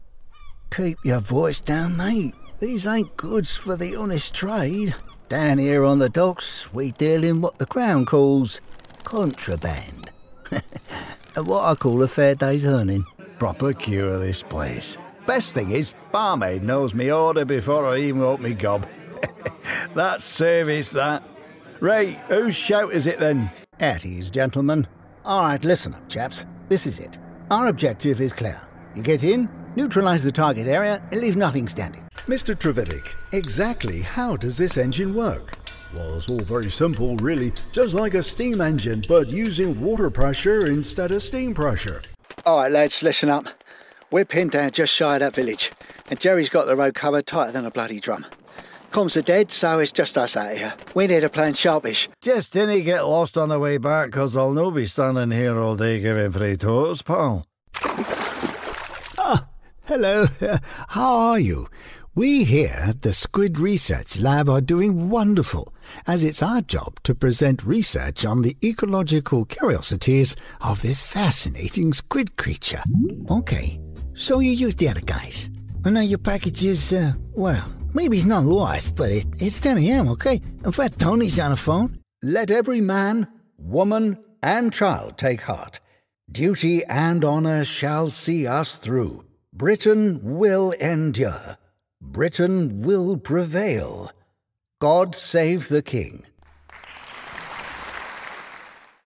I have a natural accent free (RP) style voice which is flexible and can adapt to most voiceover projects.
Rode NT1a Condensor Mic, Mac Mini m4, Adobe Audition CC, Scarlett Solo Interface, Tannoy 405 Studio monitors, Sennheiser HD 280 Pro monitoring headphones.
Deep